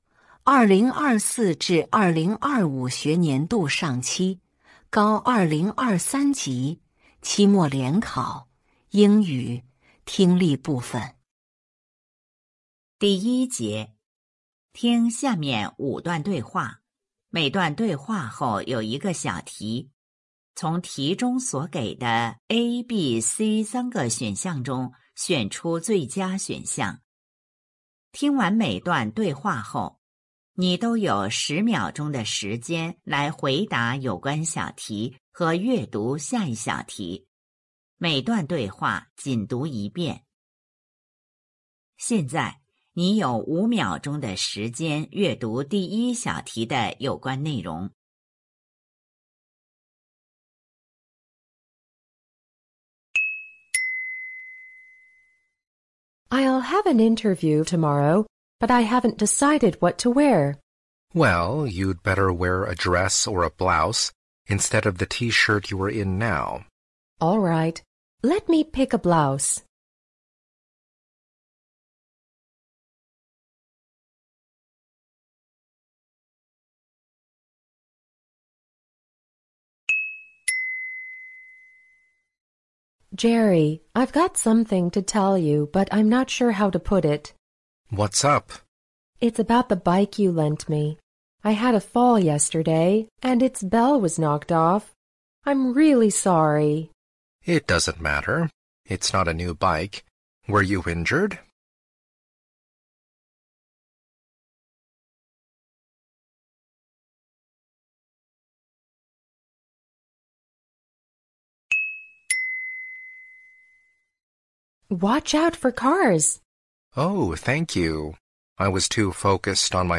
四川省名校联盟2026届高二上学期期末联考 英语听力.mp3.mp3